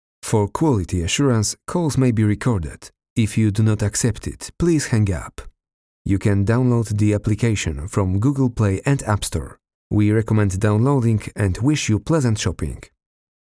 Mężczyzna 30-50 lat
Demo lektorskie
Komunikat głosowy w języku angielskim